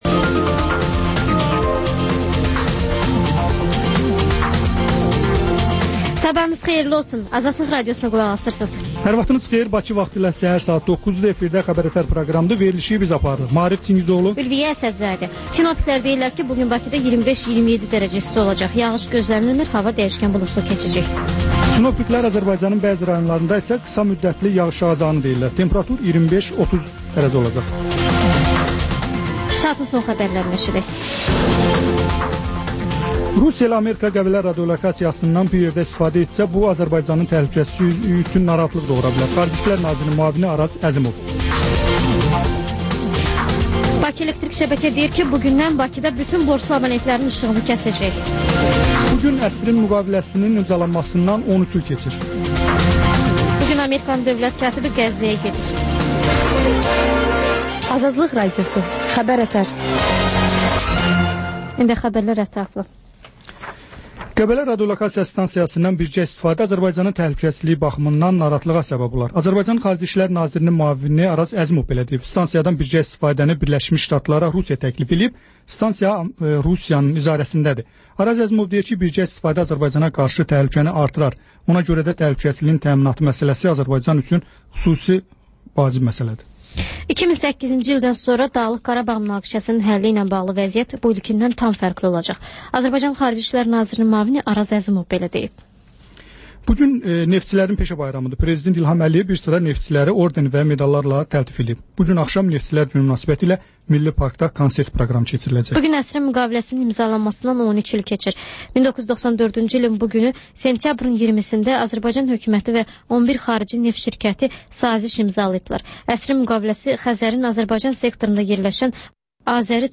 Xəbər-ətər: xəbərlər, müsahibələr, daha sonra ŞƏFFAFLIQ: Korrupsiya haqqında xüsusi veriliş.